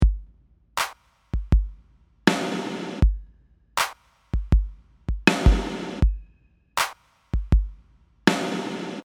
Regular, just listening to the reverb:
gatereverb.mp3